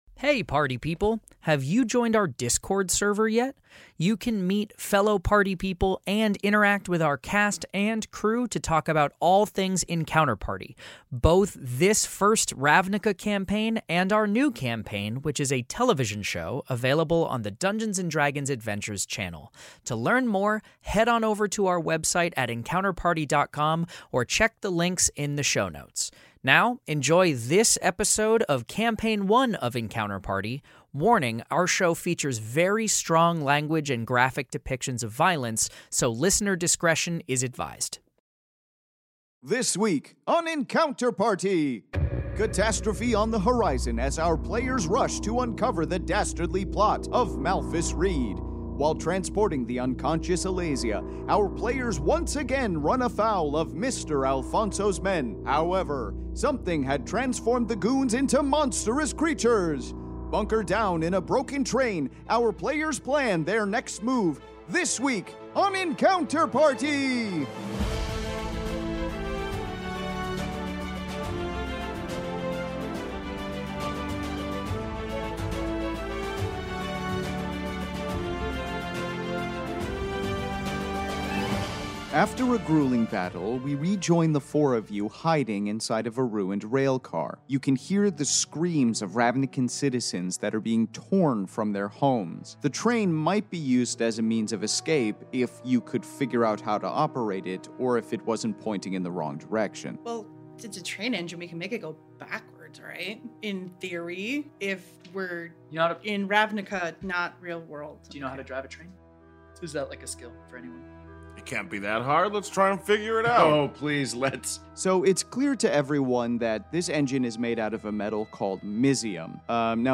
Step inside the Magic: the Gathering world of Ravnica in this Fantasy Mystery Audio Adventure governed by the rules of Dungeons & Dragons